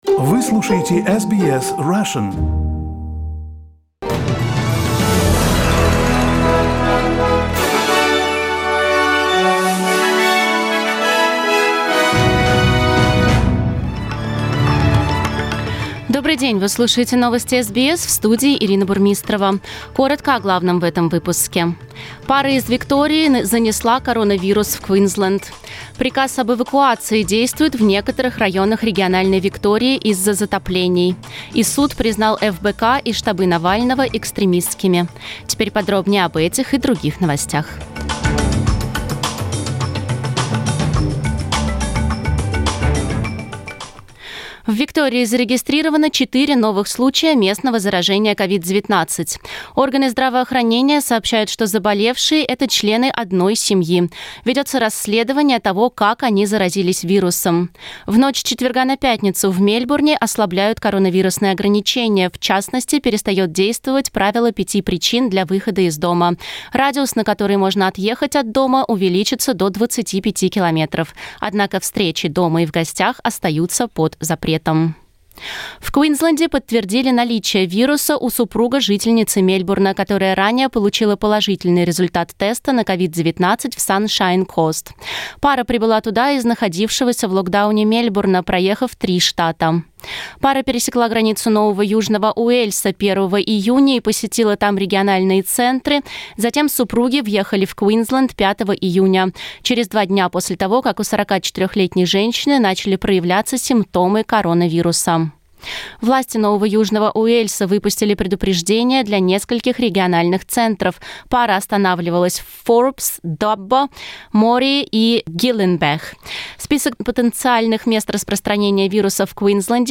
Новости SBS на русском языке - 10.06